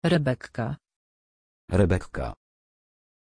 Pronunția numelui Rebekka
pronunciation-rebekka-pl.mp3